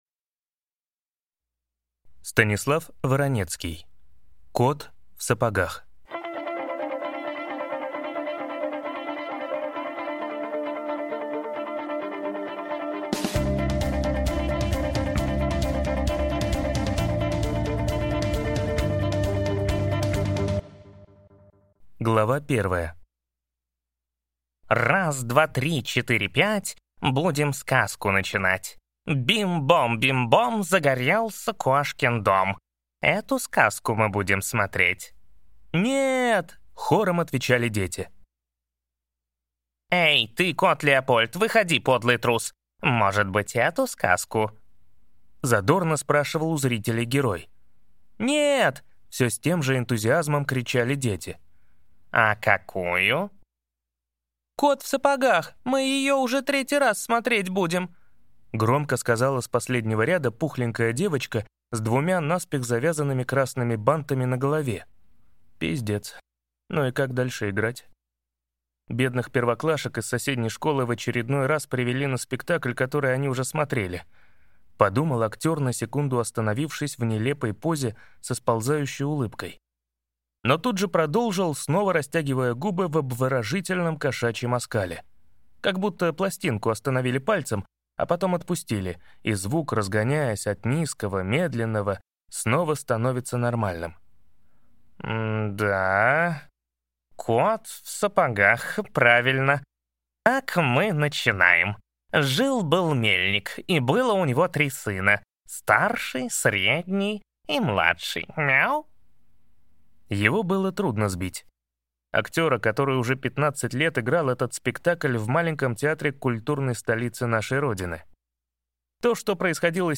Аудиокнига Кот в сапогах | Библиотека аудиокниг